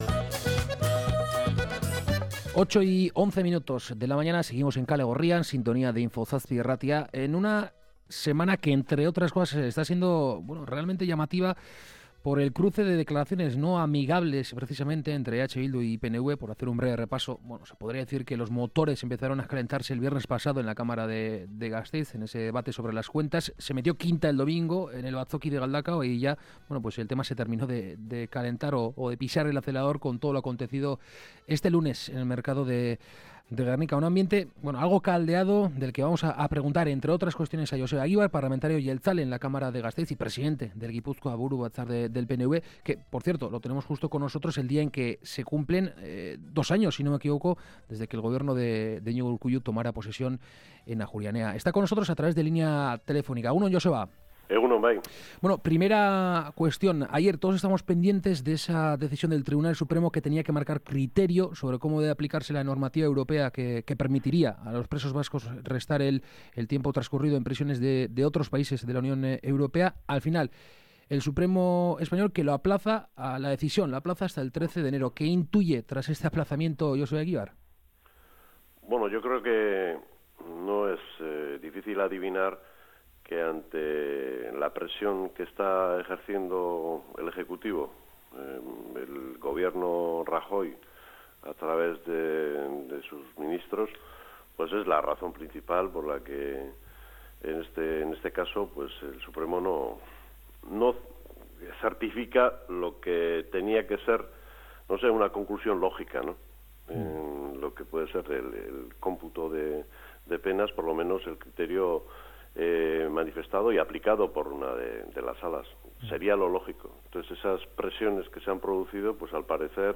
Entrevista con Joseba Egibar, le hemos preguntado sobre la actualidad política.